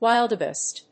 音節wil・de・beest 発音記号・読み方
/wíldəbìːst(米国英語)/